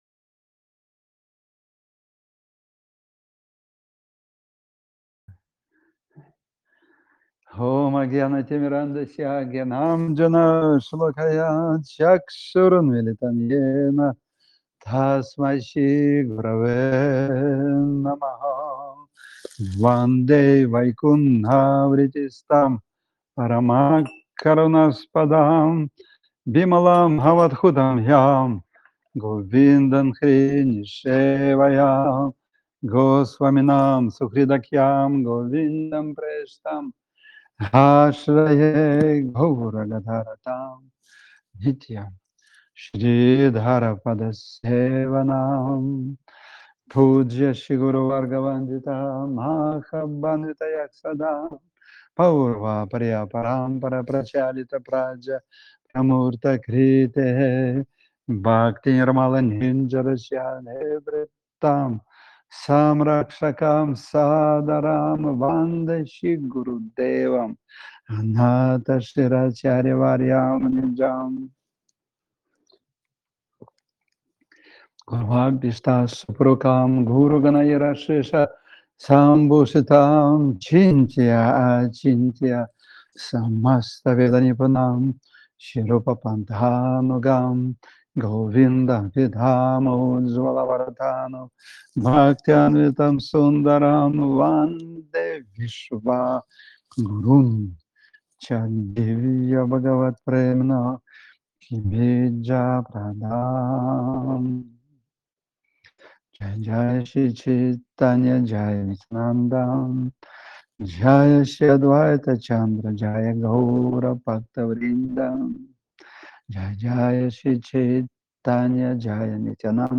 Лекции полностью